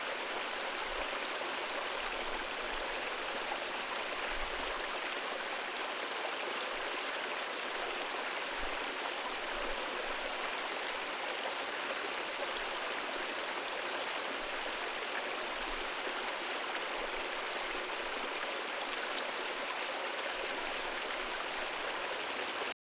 Shallow Creek Fast.wav